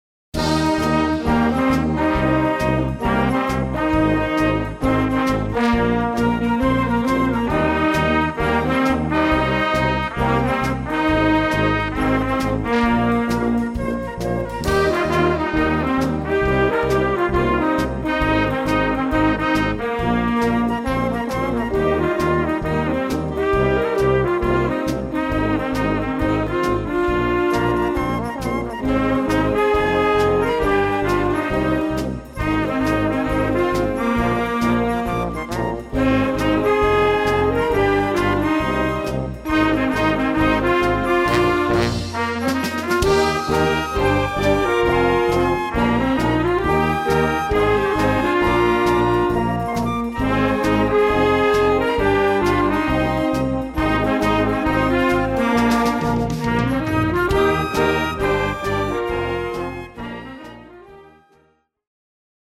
Youth Wind Band